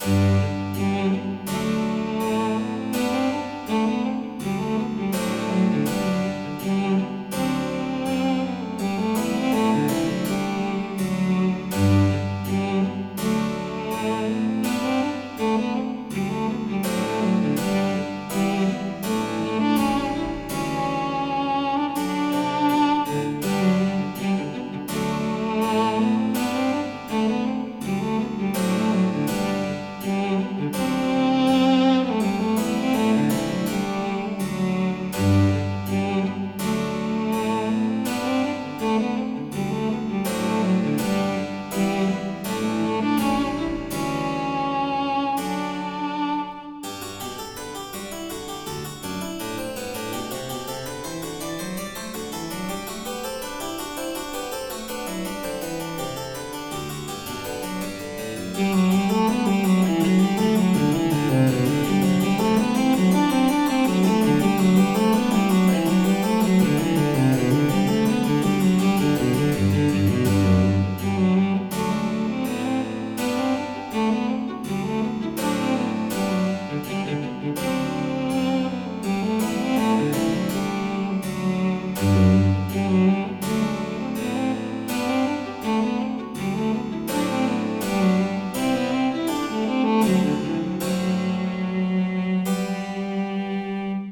ファンタジー系フリーBGM｜ゲーム・動画・TRPGなどに！
最初のほうは通奏低音っぽくした。